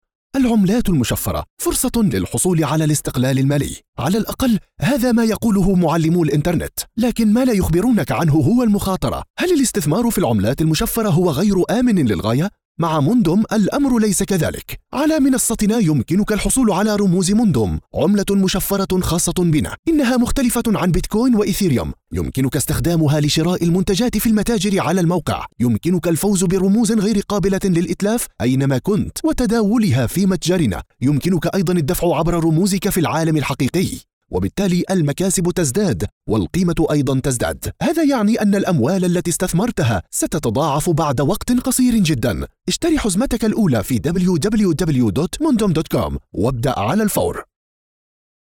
Mundum corporate VO
Mundum corporate video VO, explainer video for Mundum services Co.